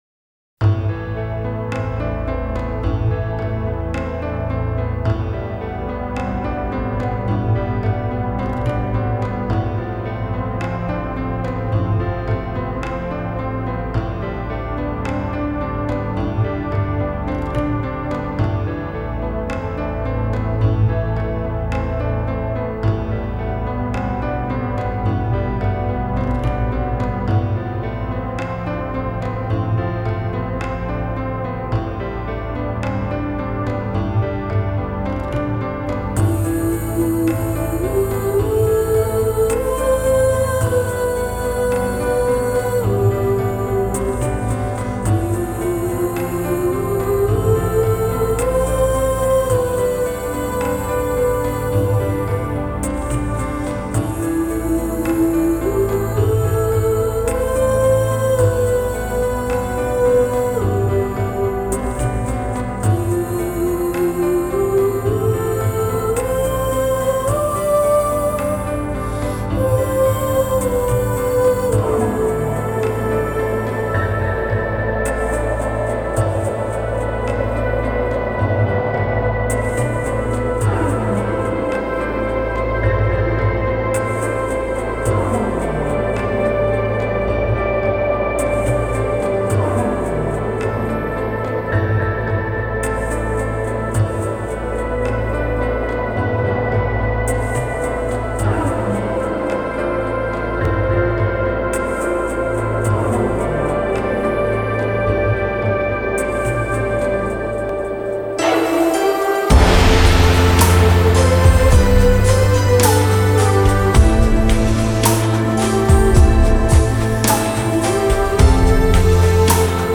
一首清脆.空灵且很好听的曲子